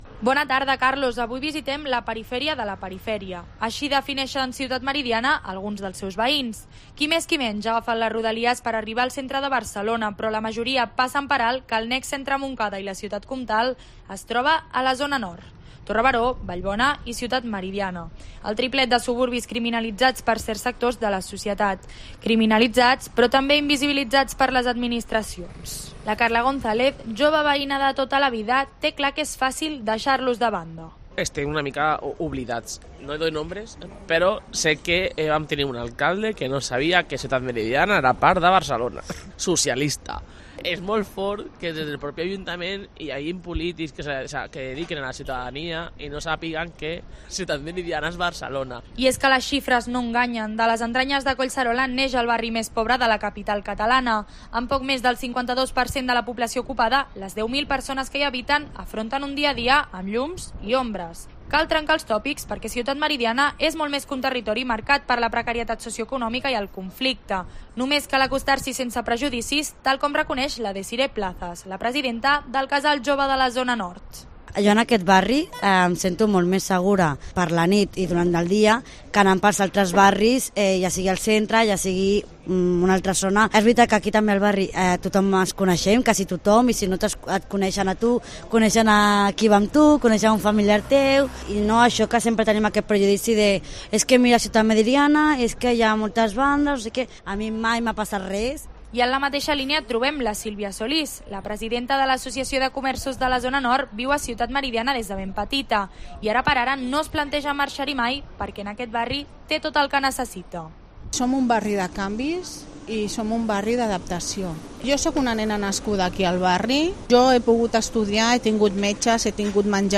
Una vecina de Ciutat Meridiana,: “Tuvimos un alcalde que no sabia que formábamos parte de Barcelona”
Desde COPE Catalunya, hemos hablado con algunos vecinos que escenifican el día a día del barrio en la actualidad.